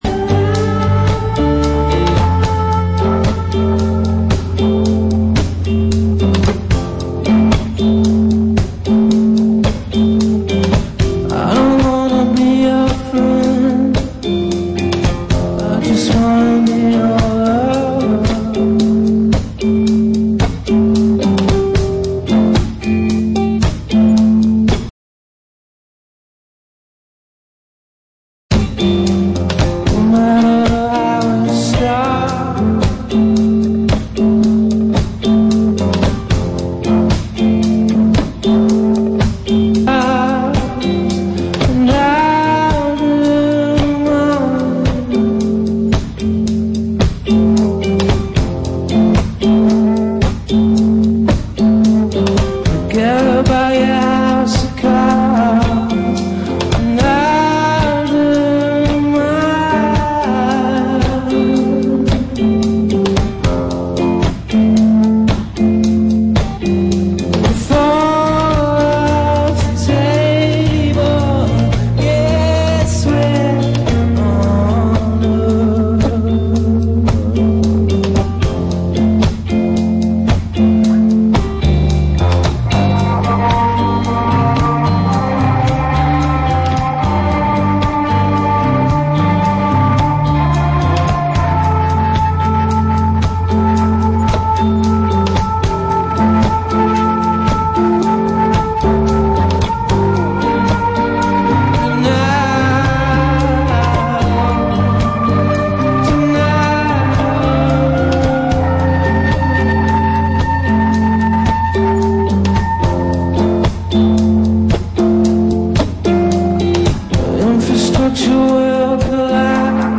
live from John Doe Books and Records in Hudson, NY